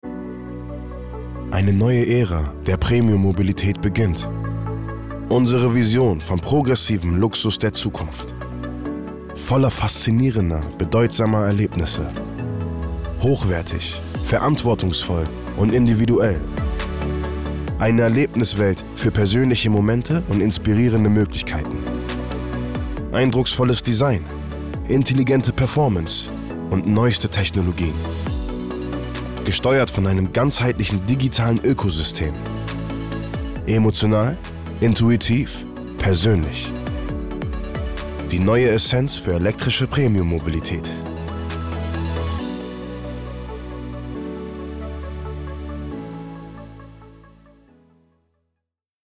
markant, dunkel, sonor, souverän, plakativ
Mittel minus (25-45)
Autowerbung